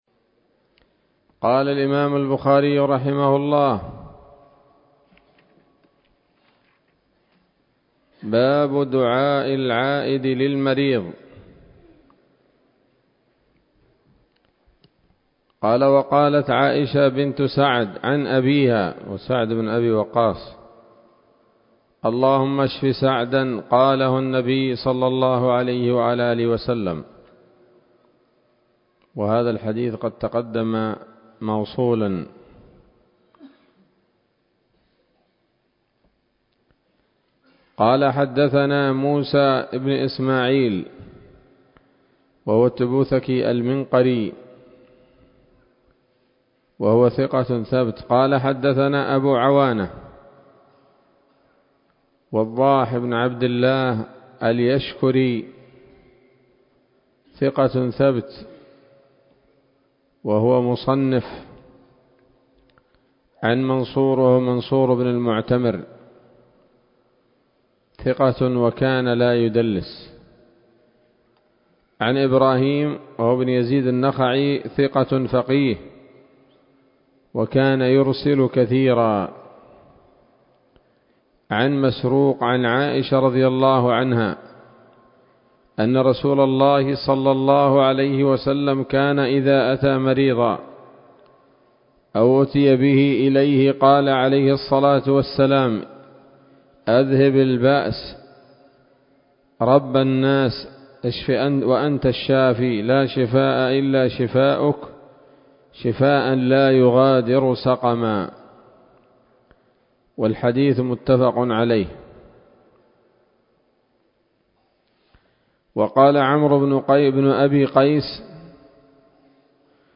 الدرس السادس عشر من كتاب المرضى من صحيح الإمام البخاري